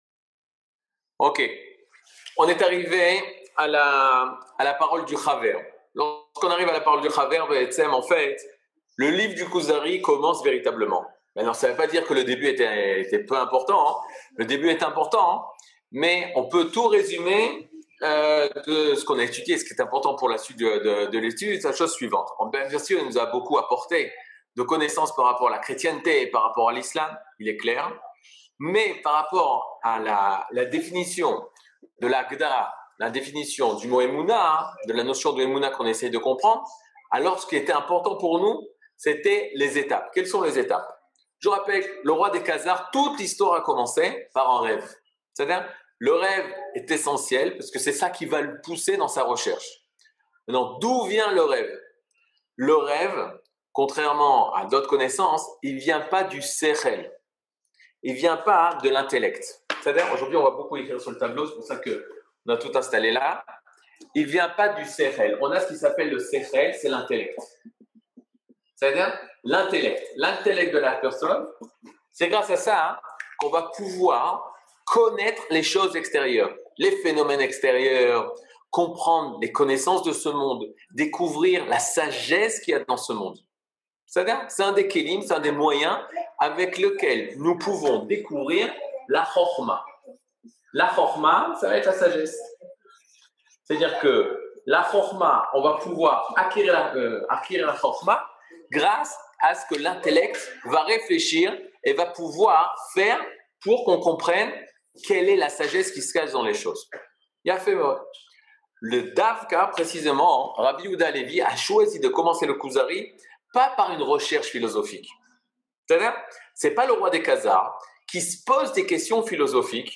Catégorie Le livre du Kuzari partie 14 00:55:59 Le livre du Kuzari partie 14 cours du 16 mai 2022 55MIN Télécharger AUDIO MP3 (51.24 Mo) Télécharger VIDEO MP4 (146.72 Mo) TAGS : Mini-cours Voir aussi ?